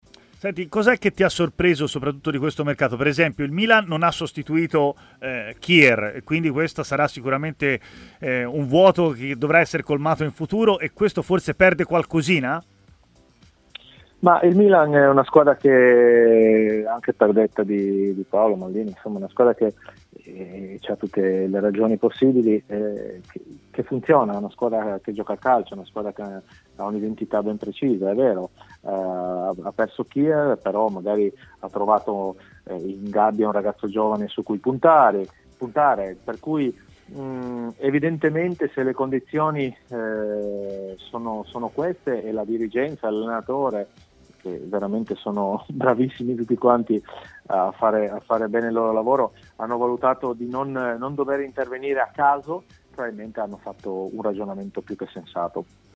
Valerio Bertotto, ex difensore, è intervenuto nel corso di Stadio Aperto, trasmissione di TMW Radio, commentando il mercato di riparazione del Milan: "La squadra funziona, gioca e ha un'identità precisa.